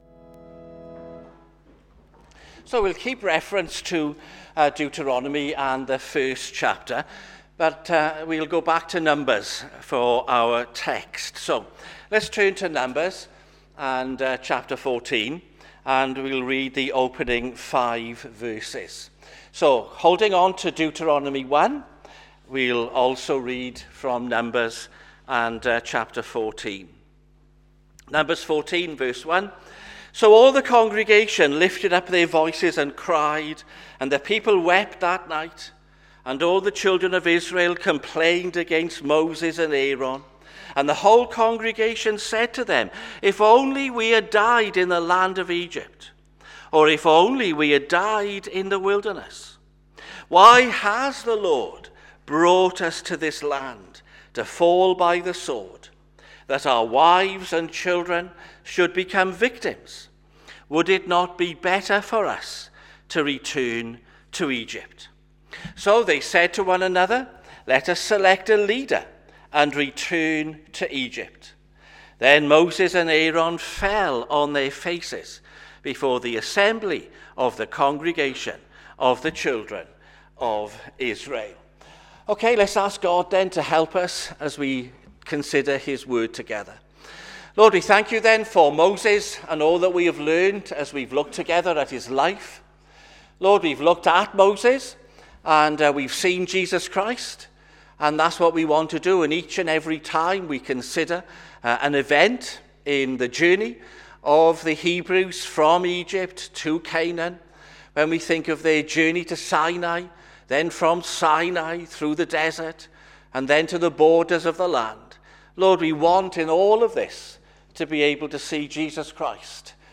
Sermons
sermon-2026-c-March-8-am.mp3